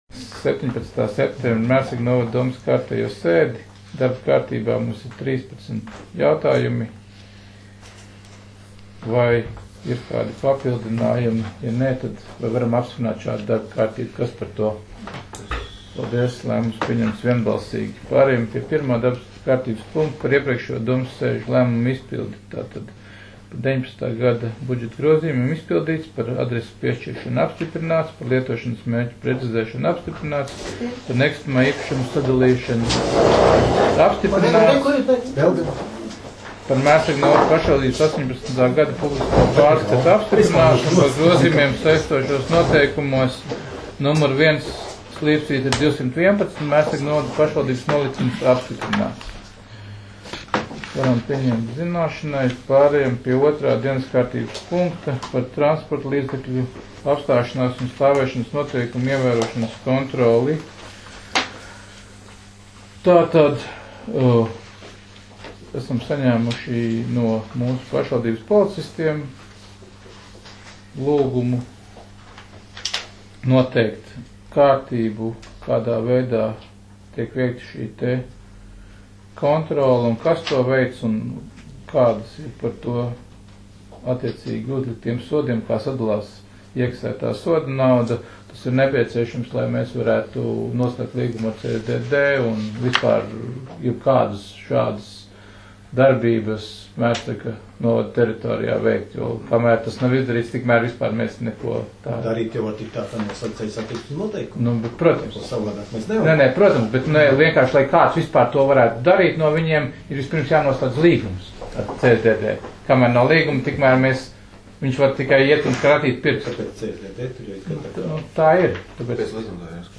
Mērsraga novada domes sēde 17.09.2019.